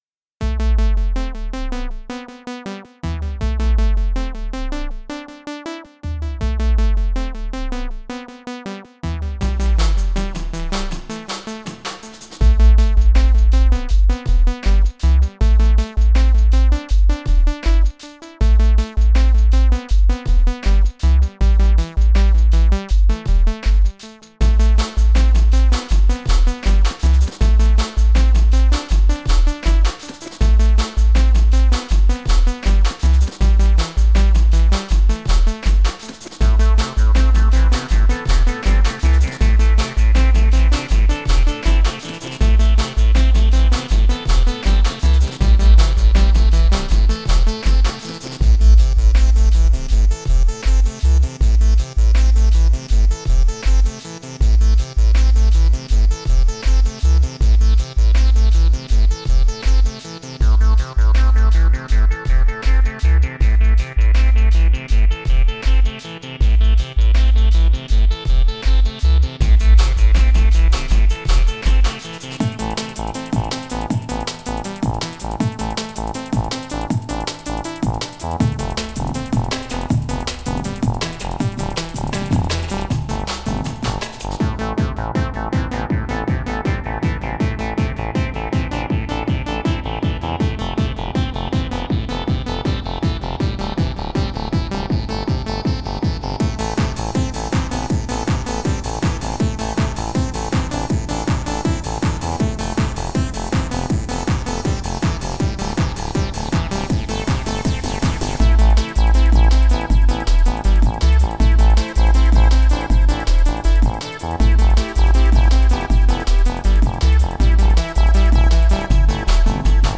dance/electronic
Created in Jeskola Buzz.
Techno
Pop